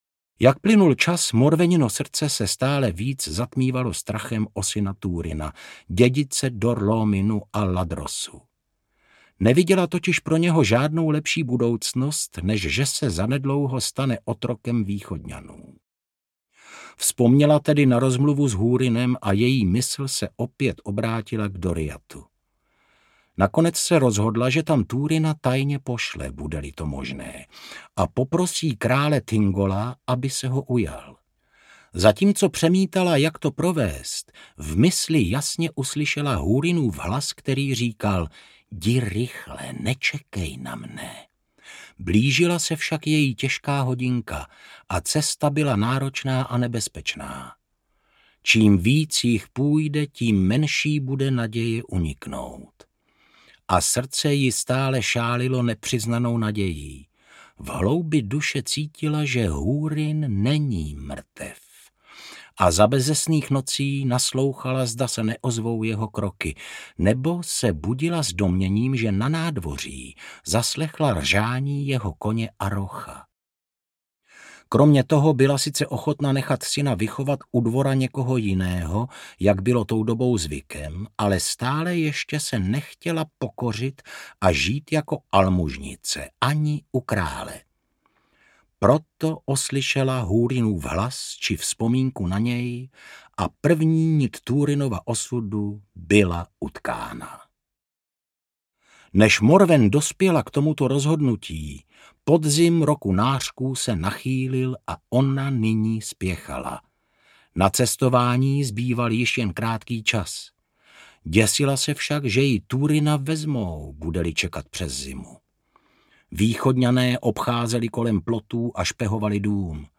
Húrinovy děti audiokniha
Ukázka z knihy
hurinovy-deti-audiokniha